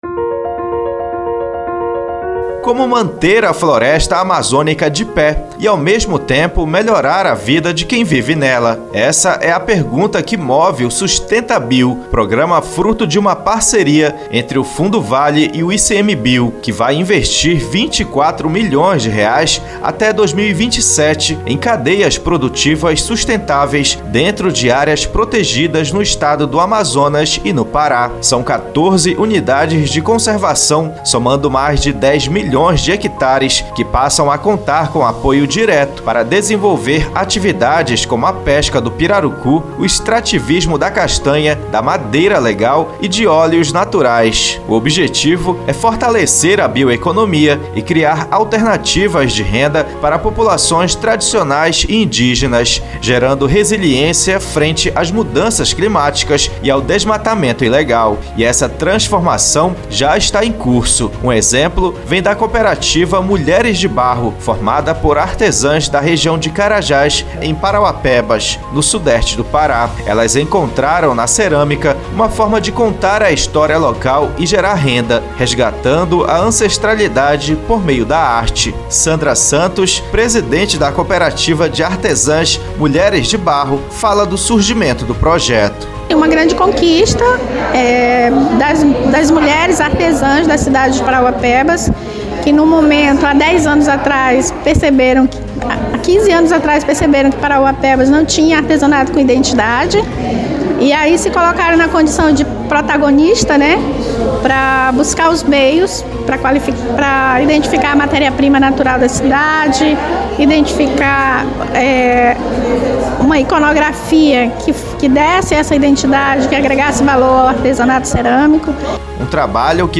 Na segunda reportagem da série, vamos conhecer como a mineração têm apoiado o empreendedorismo feminino e iniciativas sustentáveis no sudeste do Pará, e como a pesquisa e a ciência vem contribuindo para esse processo e o futuro da Amazônia